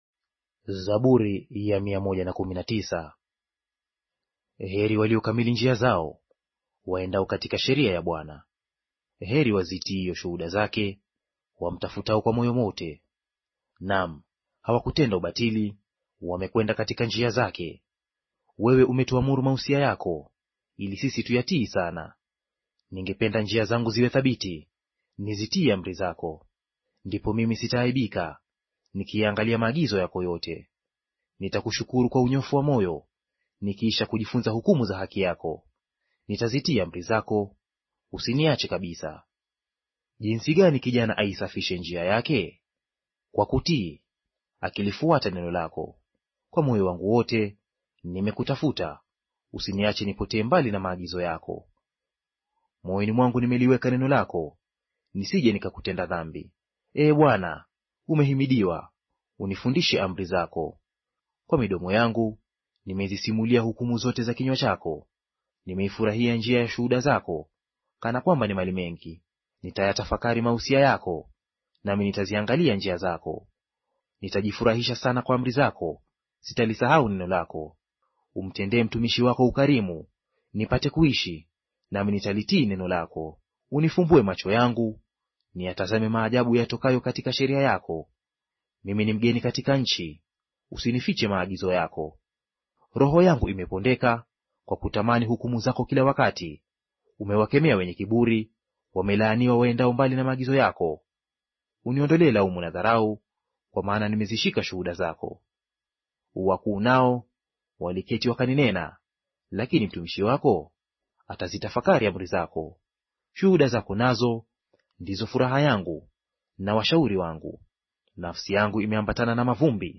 Audio reading of Zaburi Chapter 119 in Swahili